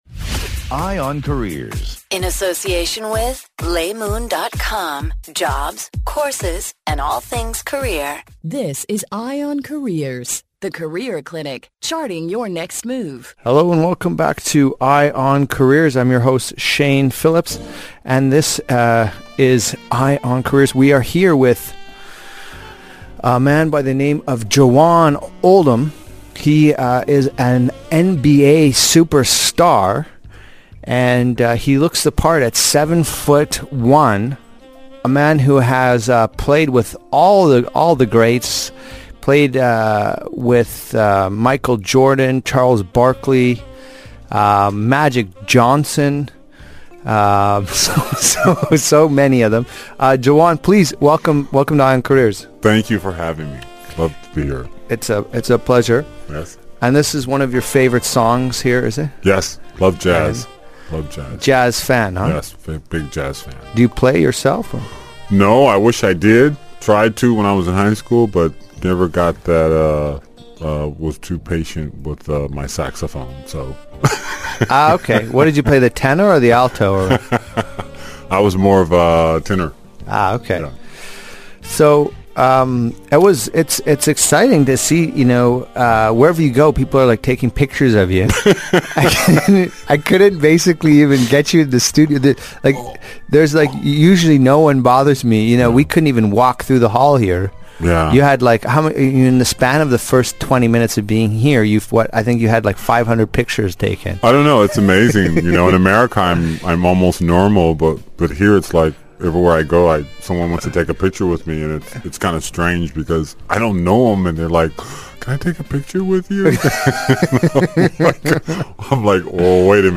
joined in studio
Jawann Oldham, American former professional basketball star.